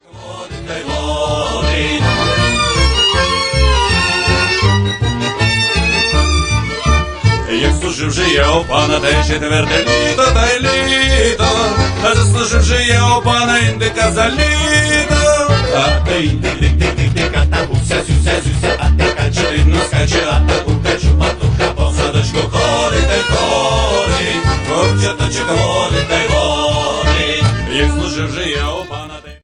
Russian jazz, swing, klezmer
charming, snappy and, of course, nostalgic